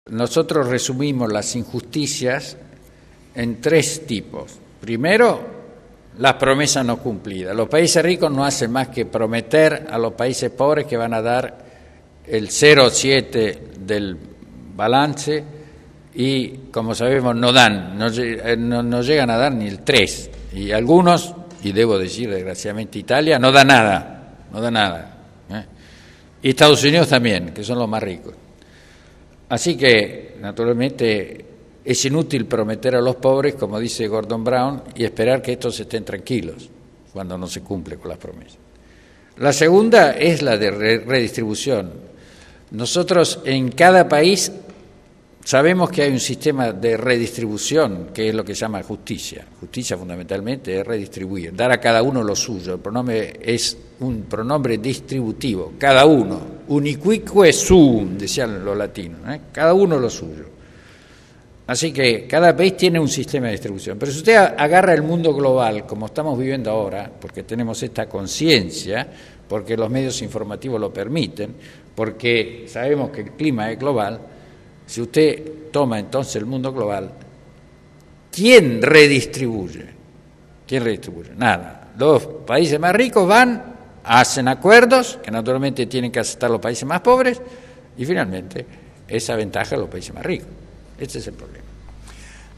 Entrevista al canciller de las Academias Pontificias de las Ciencias Sociales y de las Ciencias en la inauguración de la sesión plenaria de esta última
En una entrevista a éste último, Mons. Sánchez Sorondo nos ha subrayado la grave carencia de caridad y justicia en el mundo. Una carencia que puede resumirse en la desproporcionada redistribución de las riquezas y en las promesas no cumplidas de los países ricos.